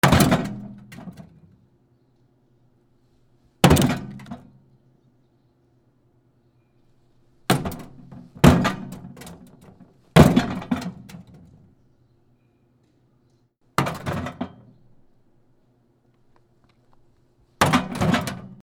電子レンジを蹴る
/ H｜バトル・武器・破壊 / H-90 ｜その他材質